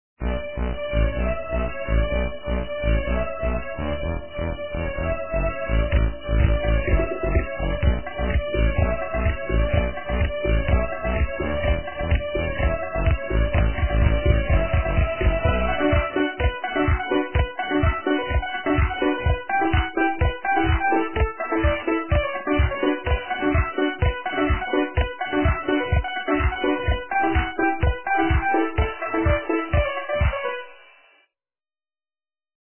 - русская эстрада
качество понижено и присутствуют гудки.